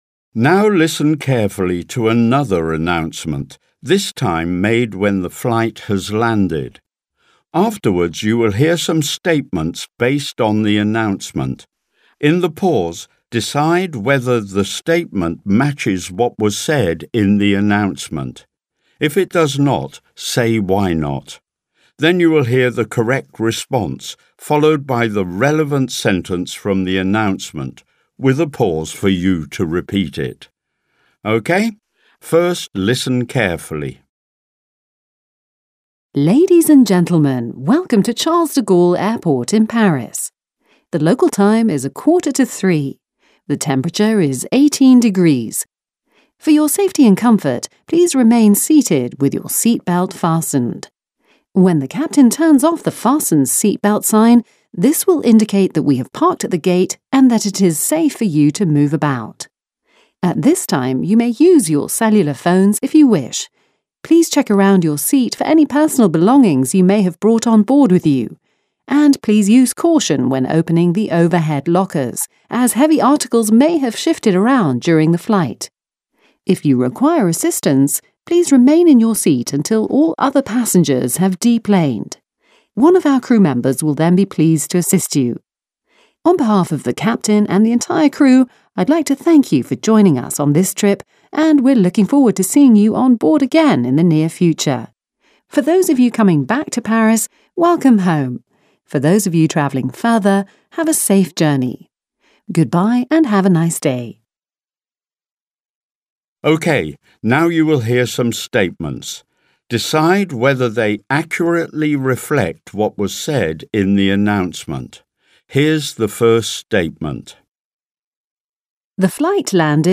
Exercise: Listening | ZSD Content Backend
Flight announcements